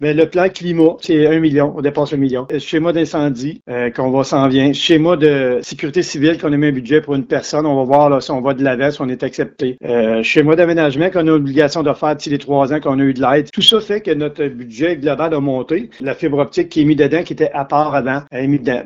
En entrevue, le préfet réélu, Mario Lyonnais a donné des précisions sur les modalités